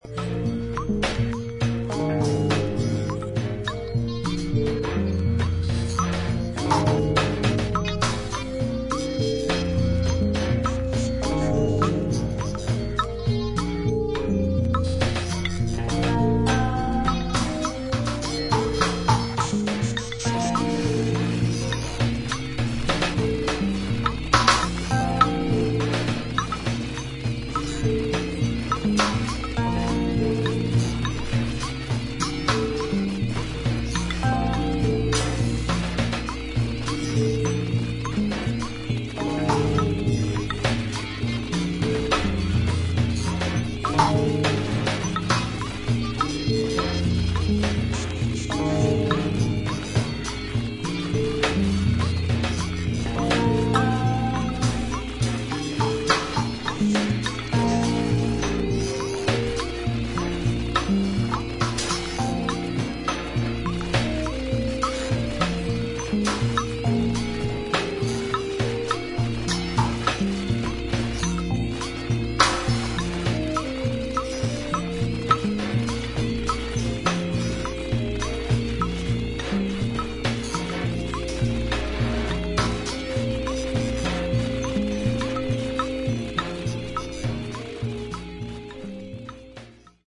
MEDIA：VG＋※B1に複数回プツッというノイズが入ります。